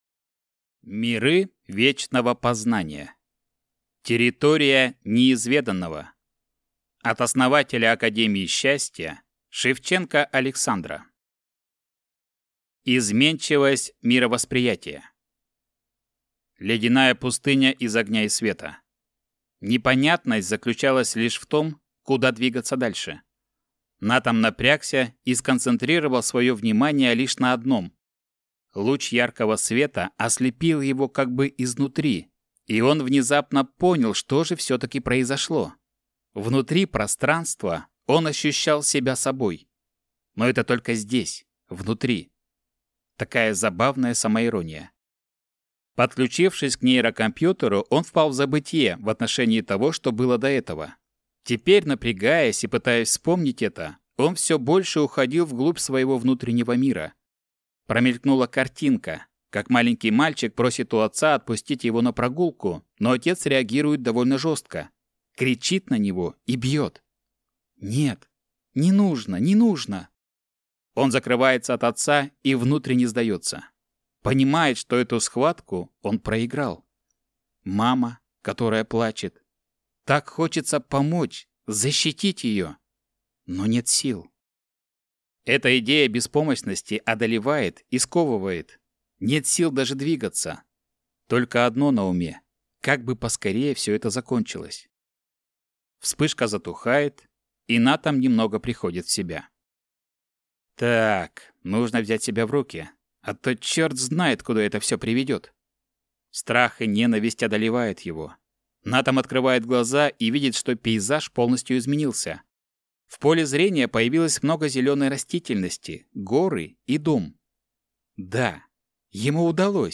Аудиокнига Миры вечного познания | Библиотека аудиокниг